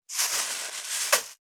603コンビニ袋,ゴミ袋,スーパーの袋,袋,買い出しの音,ゴミ出しの音,袋を運ぶ音,